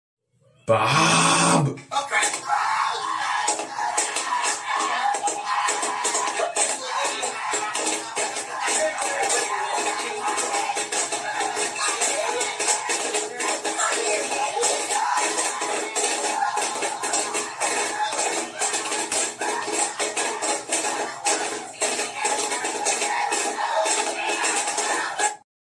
stickfigure fight cloud sound effects free download